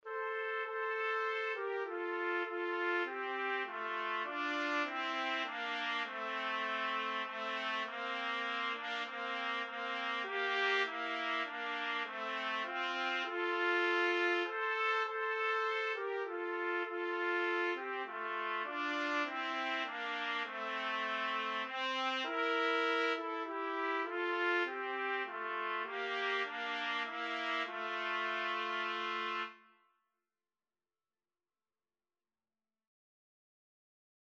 Trumpet 1Trumpet 2
3/4 (View more 3/4 Music)
Trumpet Duet  (View more Easy Trumpet Duet Music)